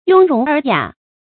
雍容爾雅 注音： ㄩㄥ ㄖㄨㄙˊ ㄦˇ ㄧㄚˇ 讀音讀法： 意思解釋： 見「雍容閑雅」。